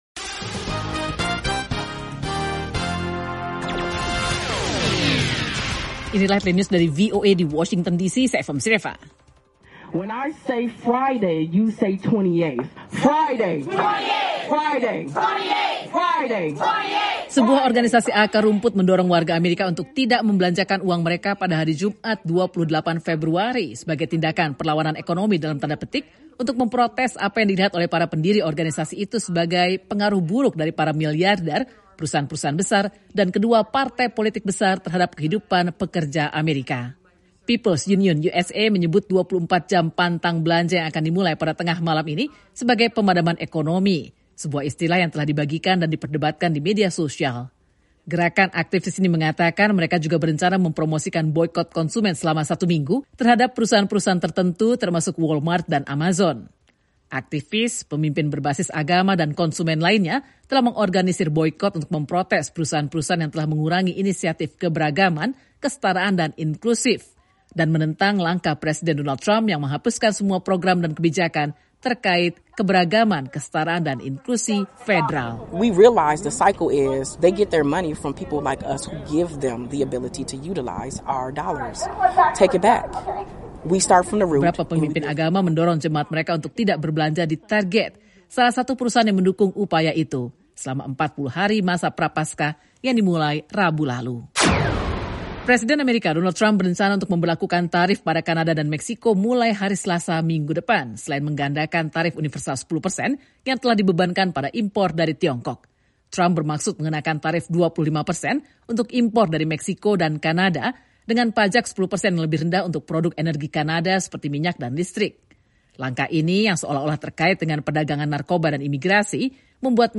Laporan Radio VOA Indonesia